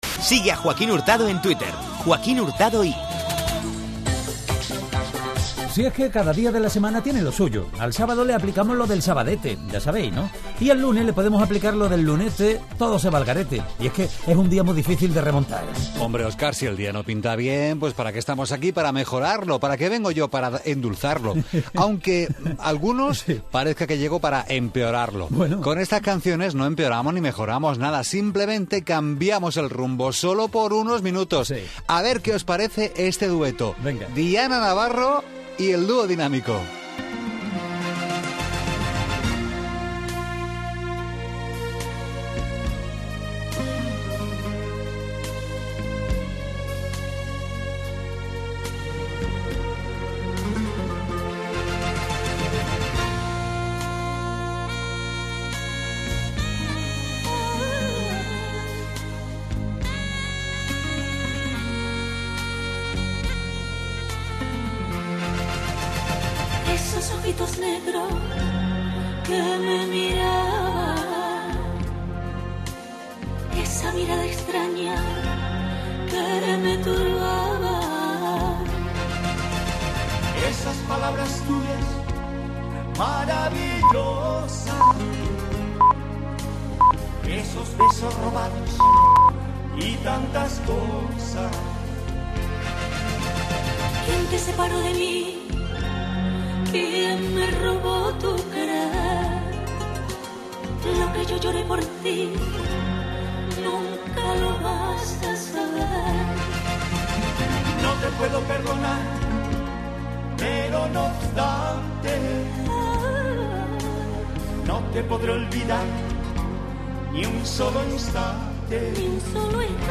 tema tan dulce